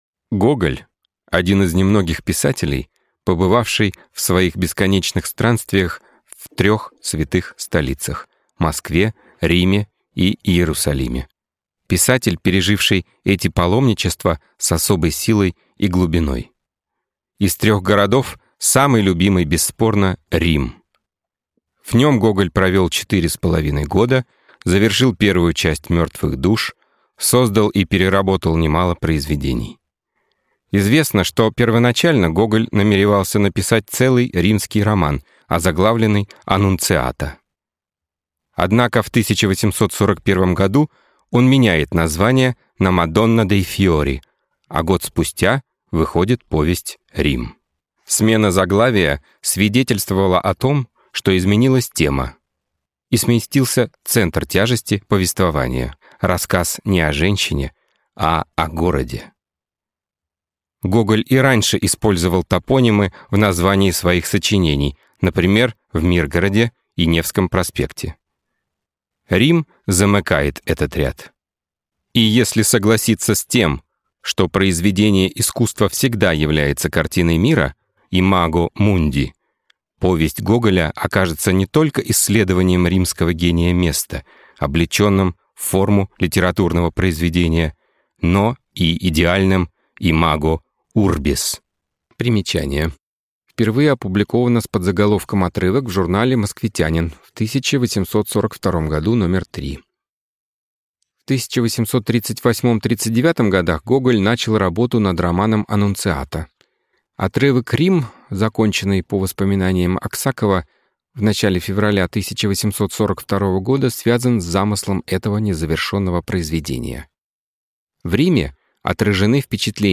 Aудиокнига Рим